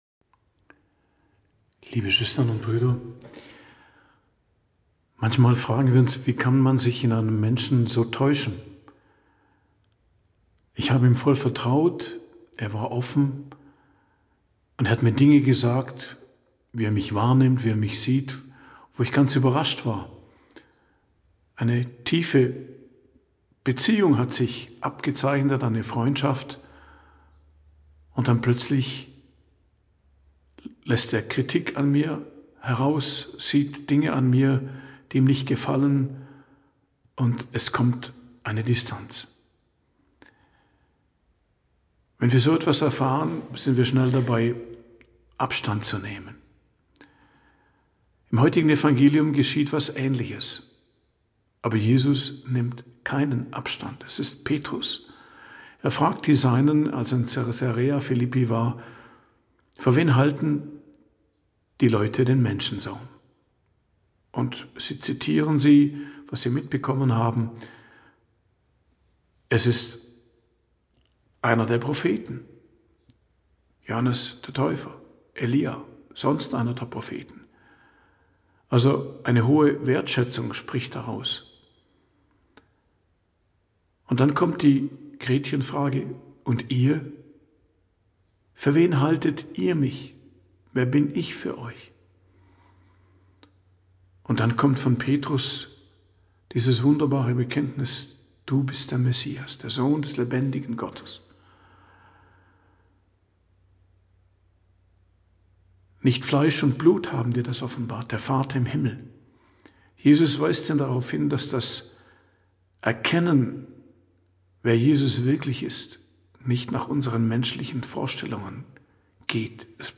Predigt am Donnerstag der 18. Woche i.J., 7.08.2025